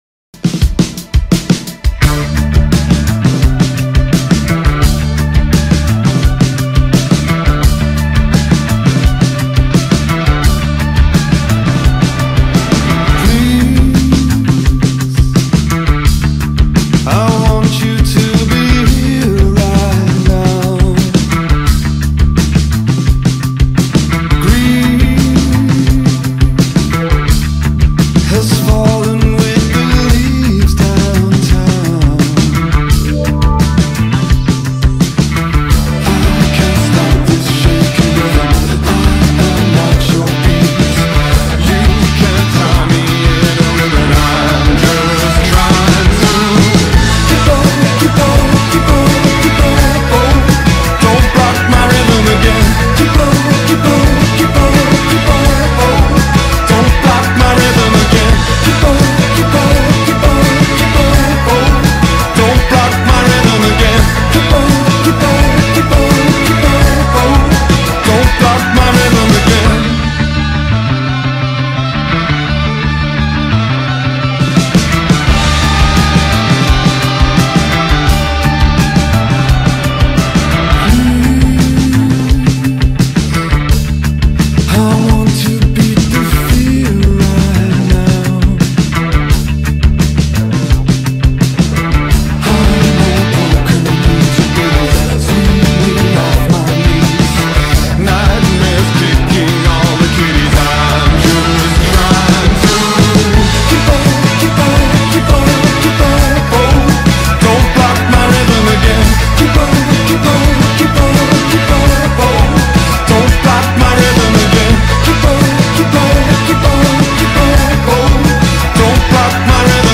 It does not rush to impress or explode just to make a point.